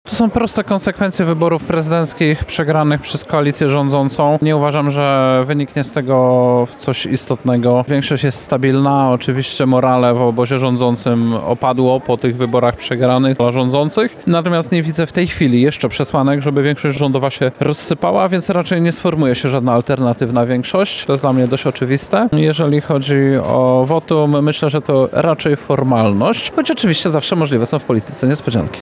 Wicemarszałek Sejmu Krzysztof Bosak gościł dziś (04.06) w Lublinie w ramach konferencji „Uwaga! Smartfon”.